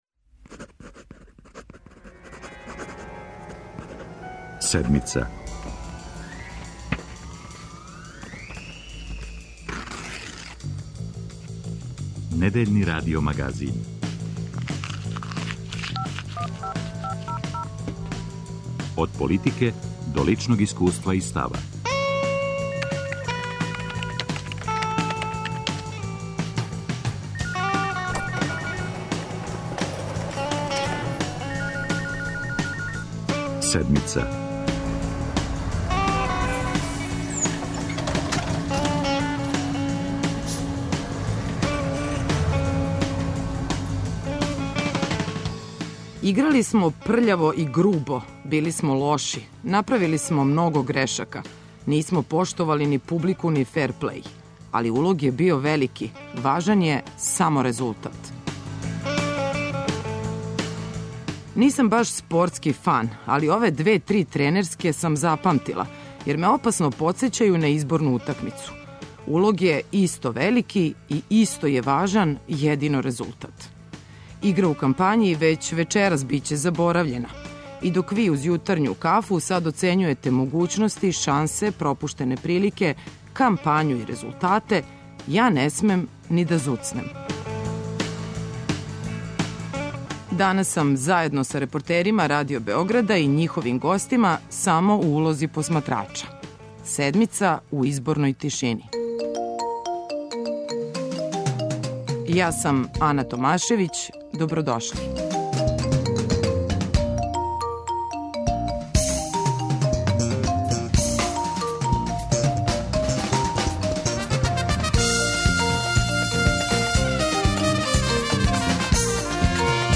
Изборни дан у Седмици - реч имају репортери Радио Београда и њихови гости. Биће на улици, код бирачких кутија или славских столова.